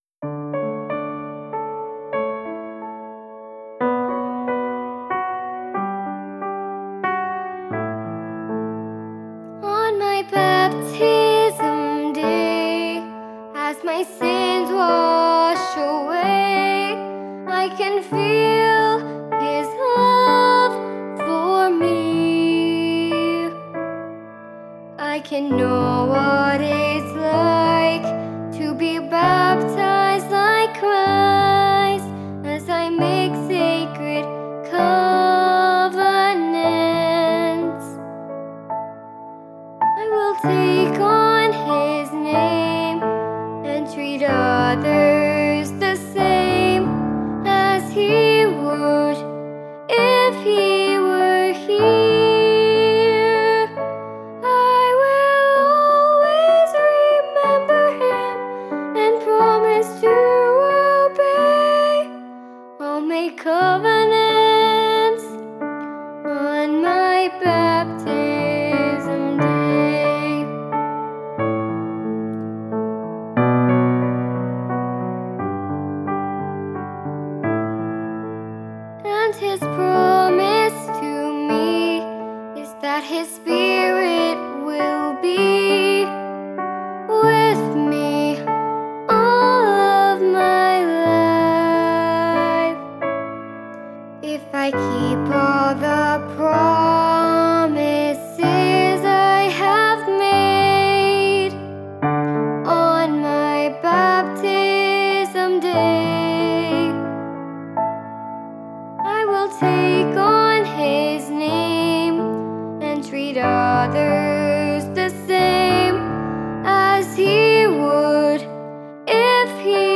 Primary Children/Primary Solo